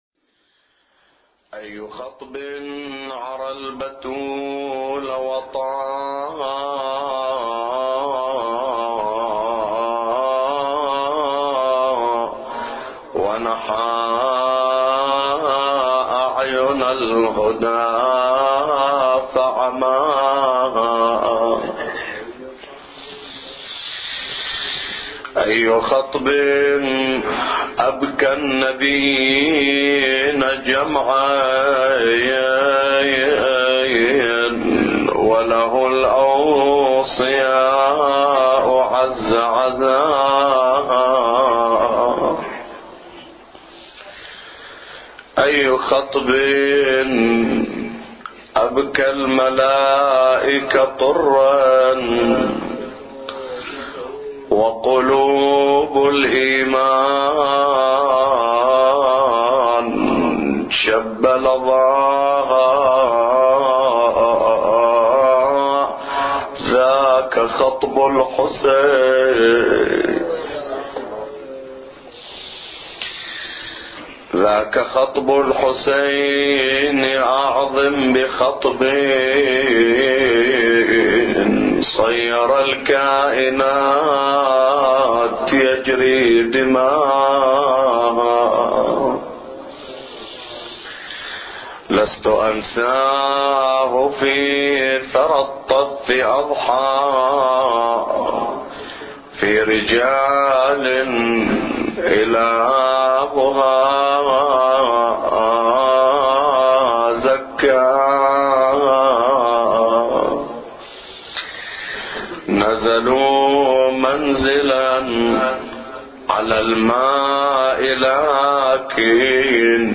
أبيات حسينية – ليلة الحادي من شهر محرم